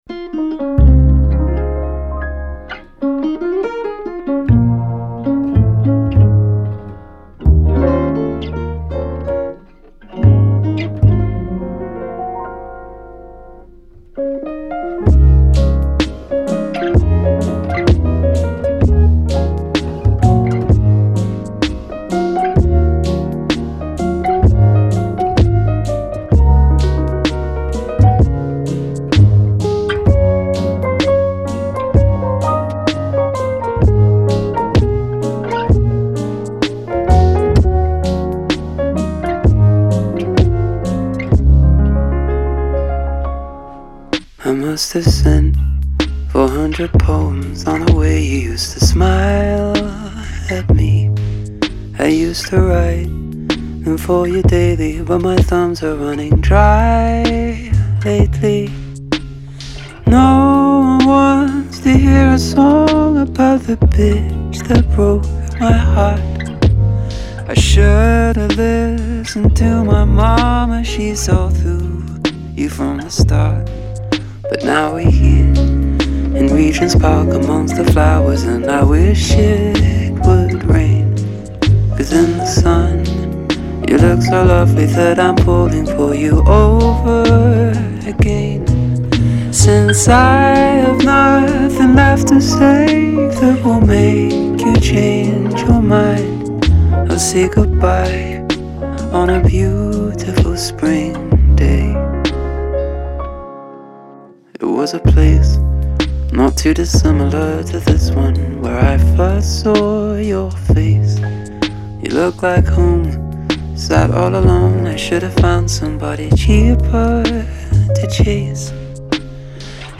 A british jazzy-pop-R&B new record
Un album léger et jazzy…